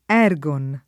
ergon [ $ r g on ]